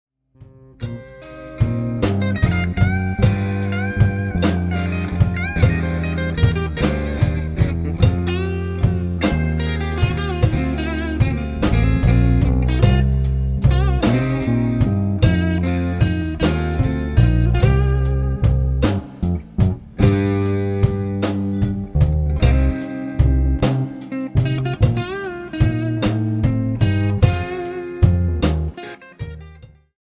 A play-along track in the style of Rock, Pop.